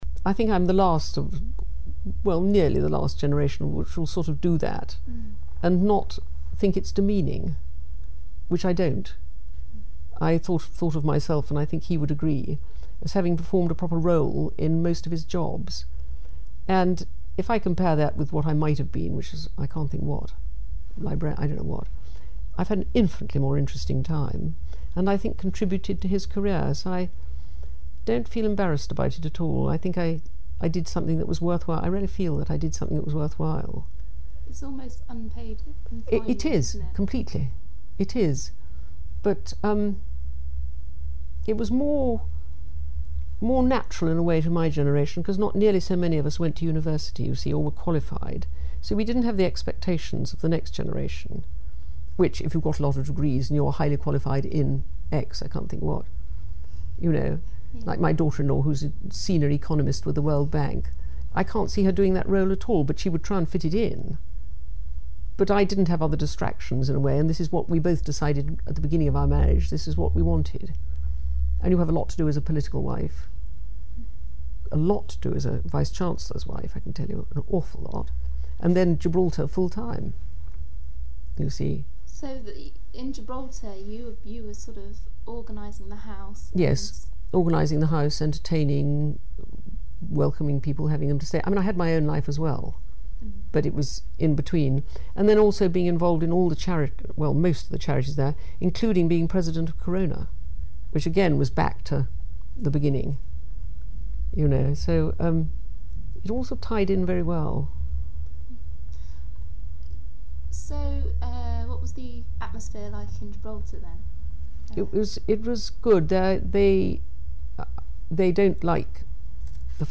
The full interview is in the BECM sound archive at Bristol City Museums Archives.